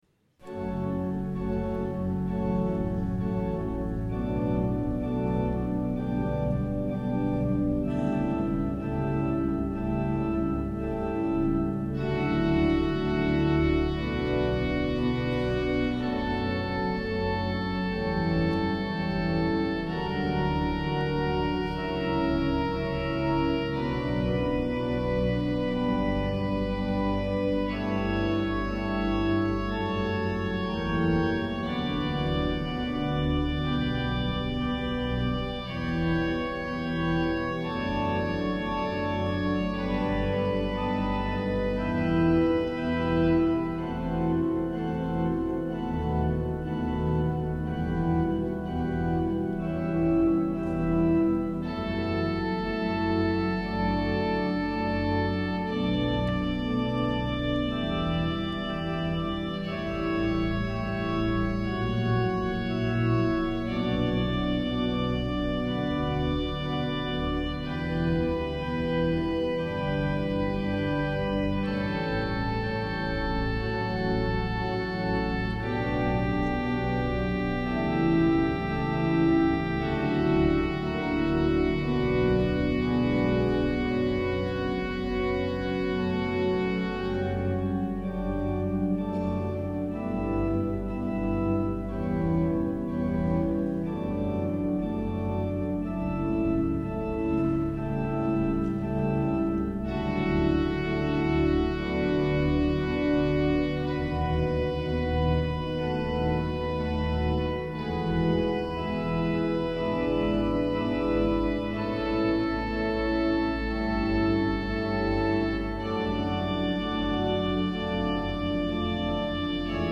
en la S.I.Catedral Metropolitana de Valladolid.